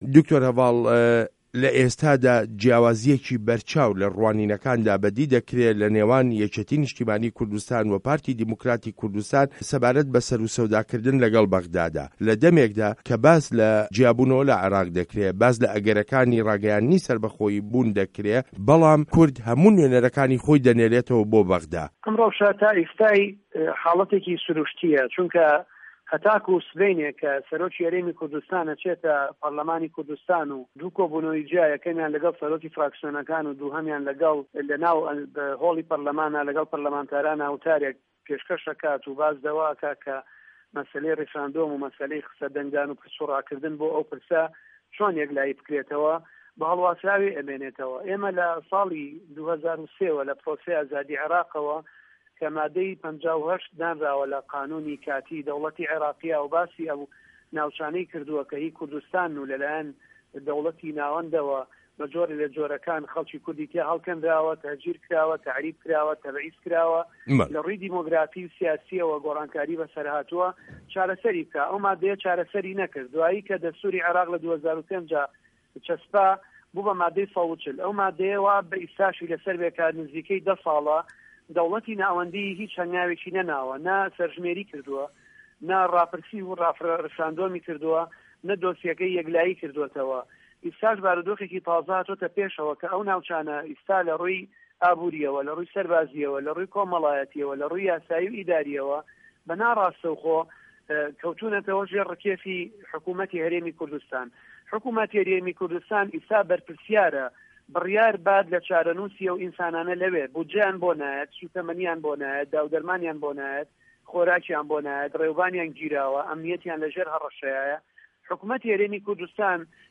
وتووێژ له‌گه‌ڵ دکتۆر هه‌ڤاڵ ئه‌بوبه‌کر